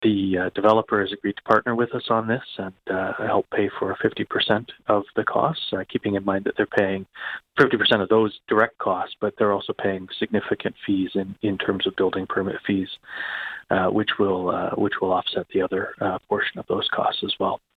Mayor Brian Ostrander says Brighton’s current building inspectors have their hands full as new home construction is booming.
brian-ostrander.mp3